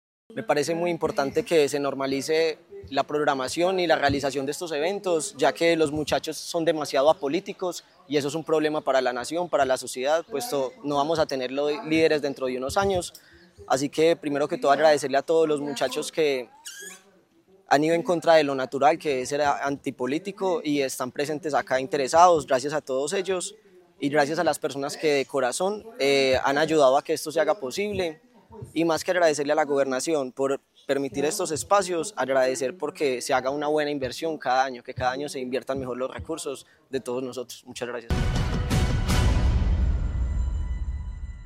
Cerca de 100 jóvenes de todo Caldas llegaron hasta Cameguadua, en Chinchiná, para asistir a la Rendición de Cuentas de Juventud 2025, liderada por la Secretaría de Integración y Desarrollo Social del departamento.